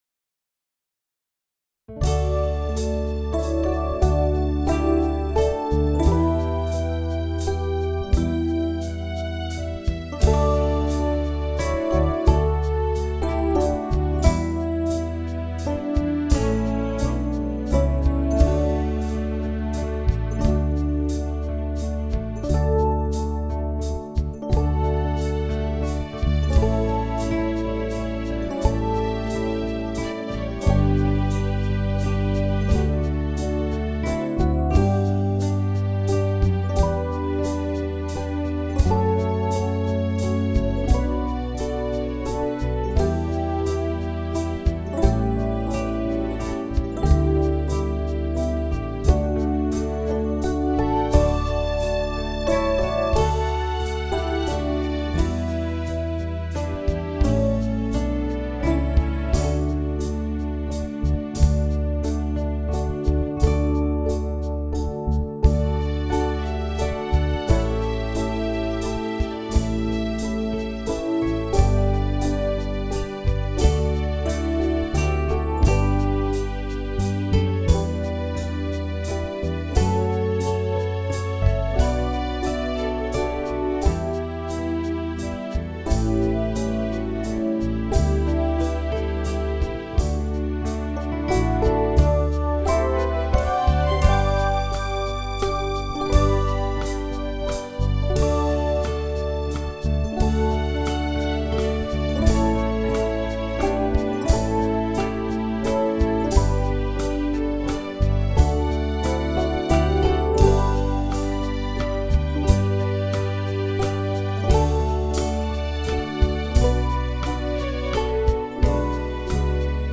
SATB-Piano